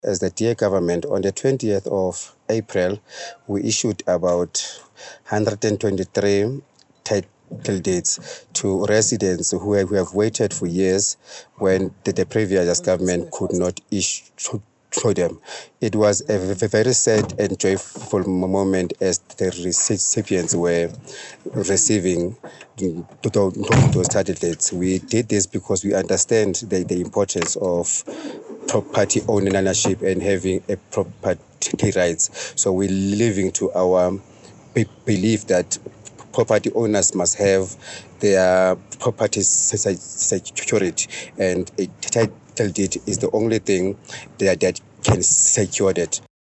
English soundbite by Cllr Thulani Mbana and